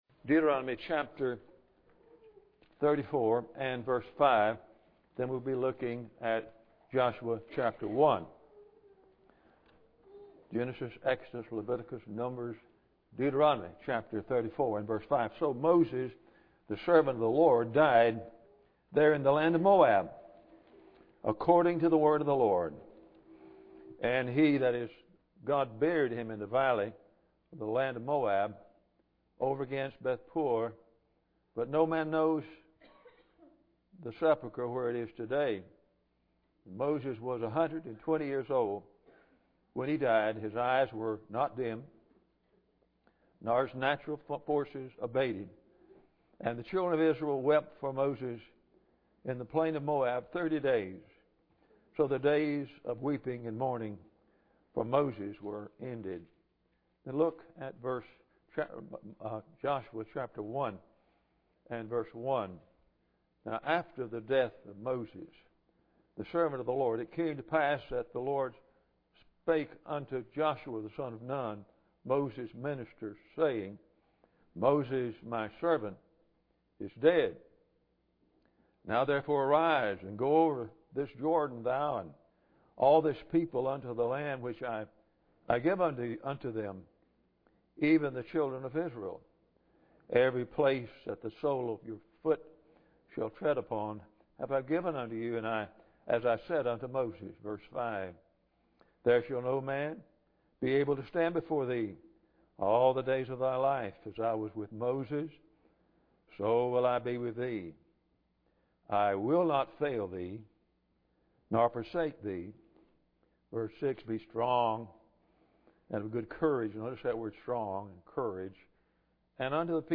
Deuteronomy 34:5-7 Service Type: Sunday Morning Bible Text